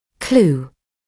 [kluː][клуː]ключ (к разгадке чего-л.); наводящая информация